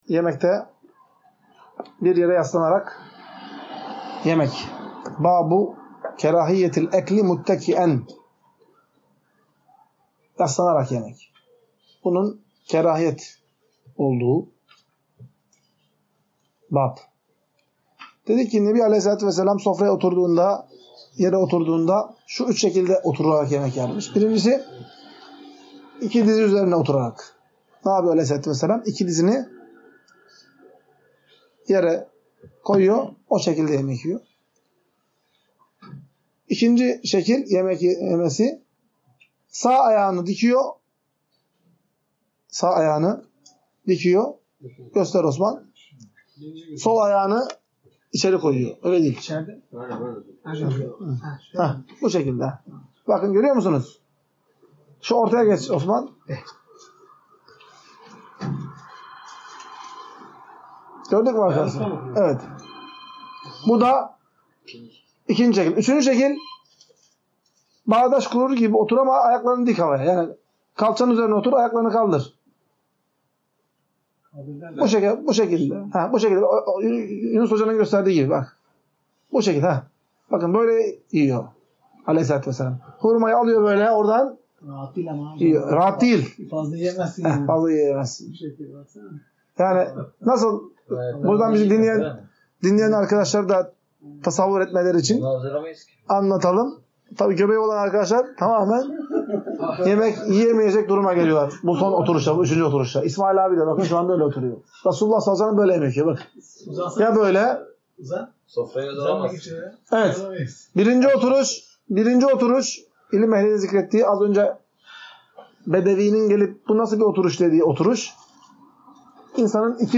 Ders - 9. Bölüm – Bir Yere Yaslanarak Yemek Yemenin Mekruh Olduğu